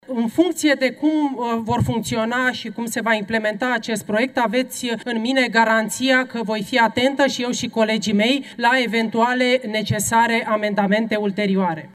Violeta Alexandru, senator USR: „Aveți în mine garanția că voi fi atentă, și eu, și colegii mei, la eventuale, necesare amendamente ulterioare”